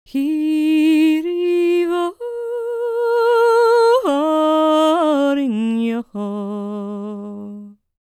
L  MOURN B06.wav